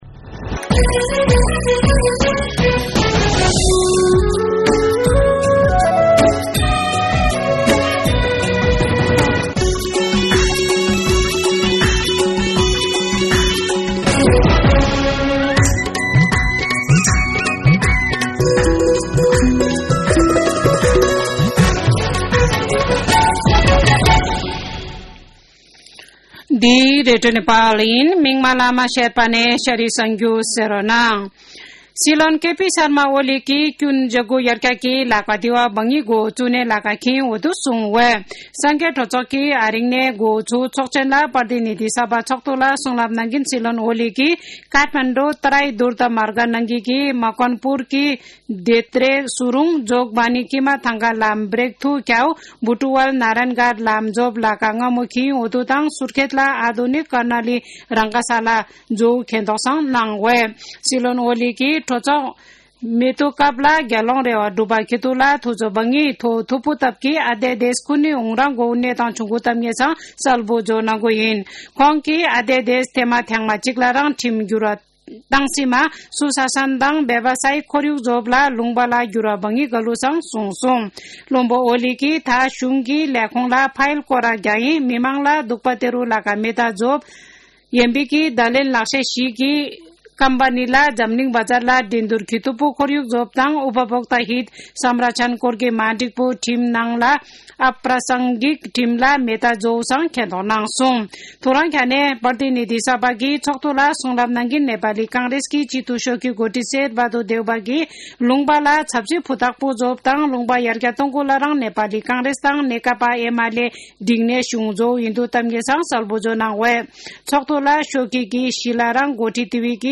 शेर्पा भाषाको समाचार : १९ माघ , २०८१
Sherpa-News-11.mp3